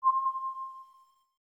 Holographic UI Sounds 63.wav